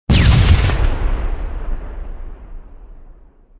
otherhit2.ogg